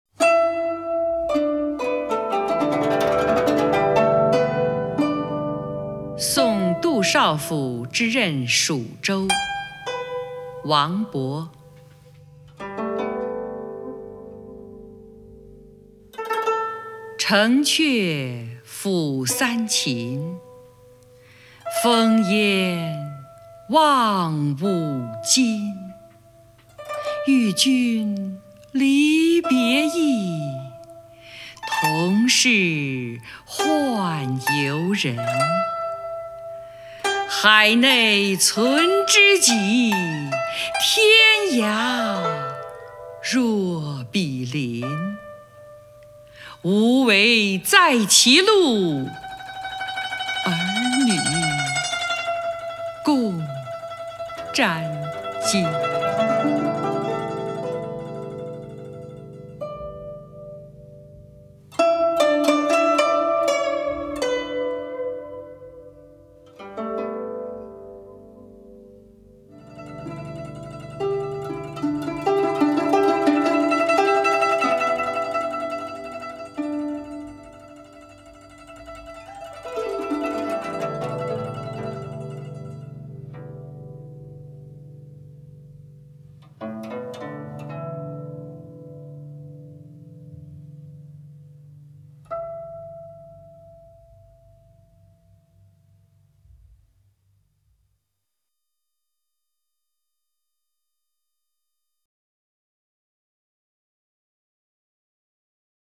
首页 视听 名家朗诵欣赏 张筠英
张筠英朗诵：《送杜少府之任蜀州》(（唐）王勃)